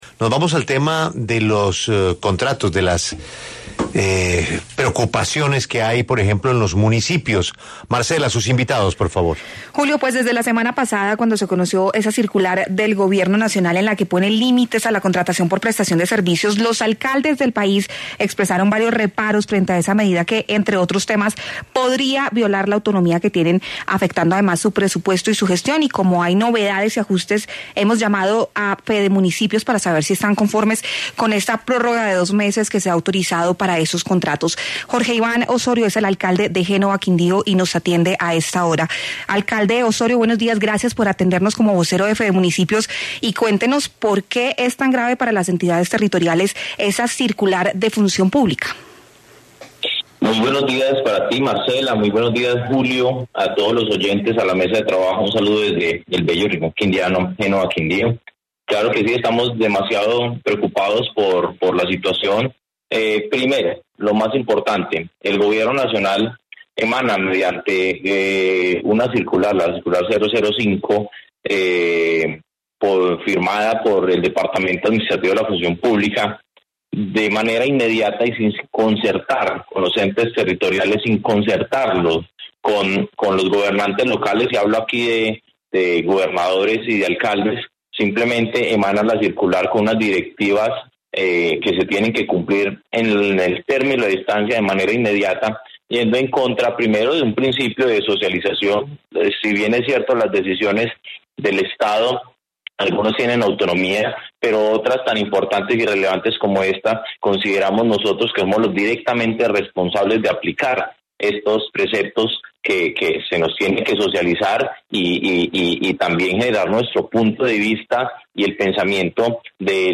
Por esto, el alcalde de Génova, Quindío, Jorge Iván Osorio, pasó por los micrófonos de La W para hablar sobre este tema.